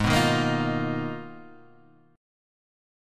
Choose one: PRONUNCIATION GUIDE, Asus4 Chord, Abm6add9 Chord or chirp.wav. Abm6add9 Chord